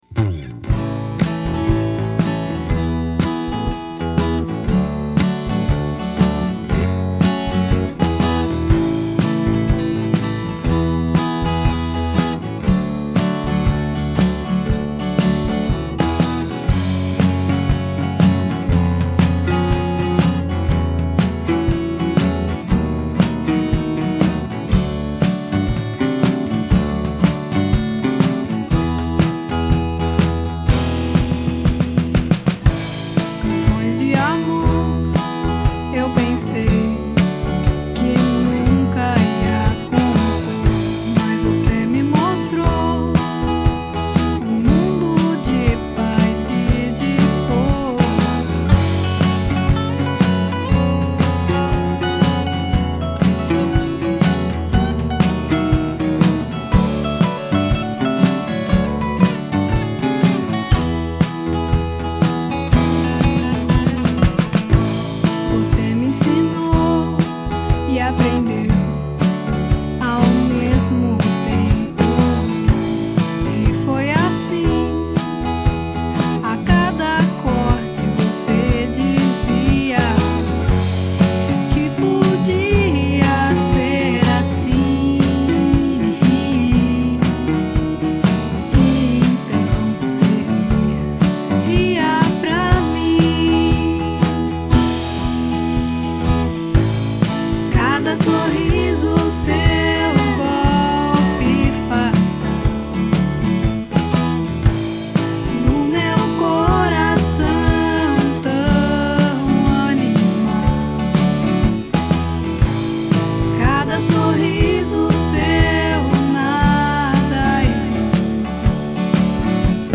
Brazilian Country/Folk song.
This song is a project of a friend of mine and was completely recorded in my house. The drums were recorded using 4 mics, and it is not a HUGE part of the song. I've played the drums and bass on this song. I've tried to sound a little bit like Ringo on the drums...especially covering the snare with a towel.